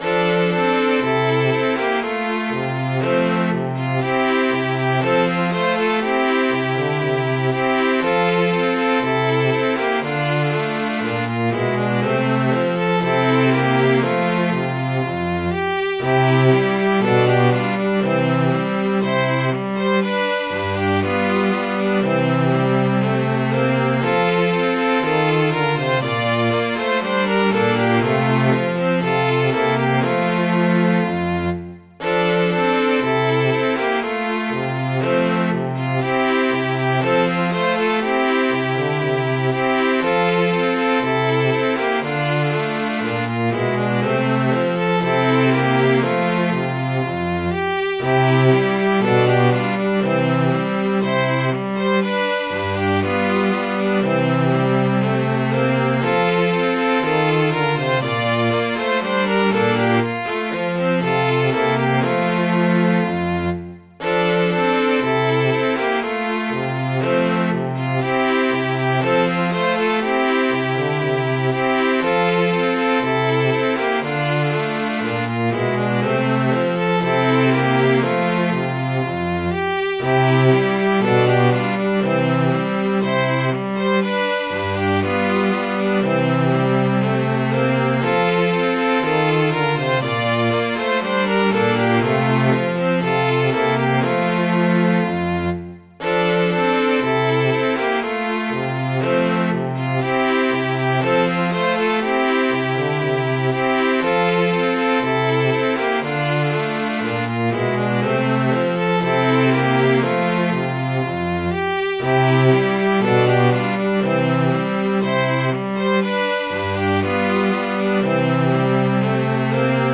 Midi File, Lyrics and Information to Woodman, Spare that Tree